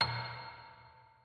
piano-sounds-dev
c7.mp3